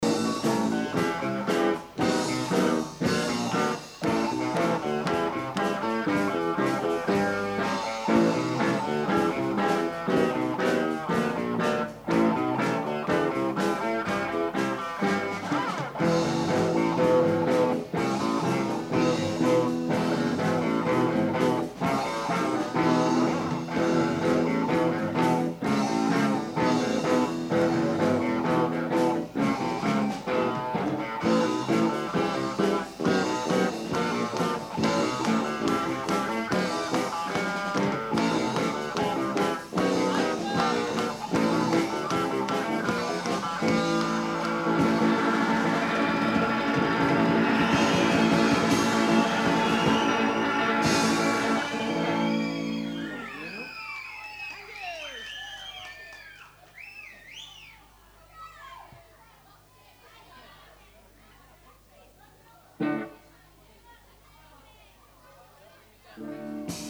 Here are the recordings from two concerts performed at Horndean School.
The first is from July 12th 1983 in the assembly hall.